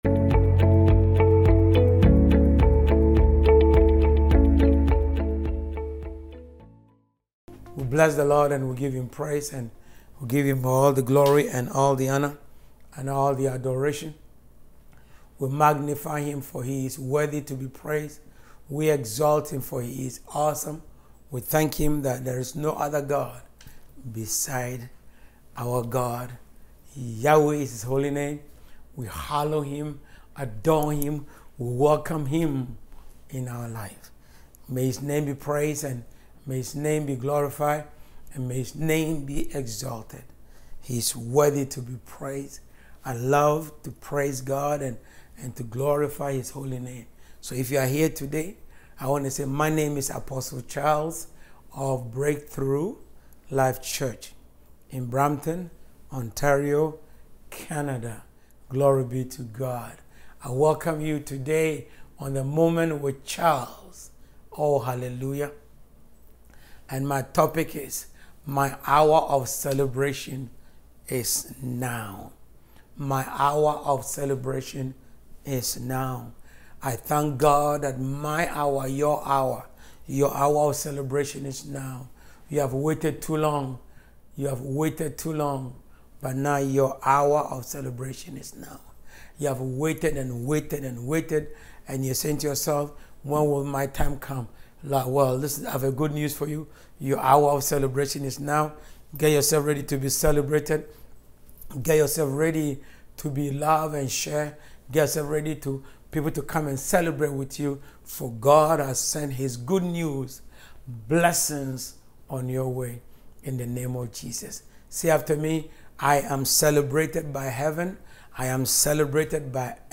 The speaker repeatedly emphasizes this message, leads prayers, and offers words of encouragement and positivity.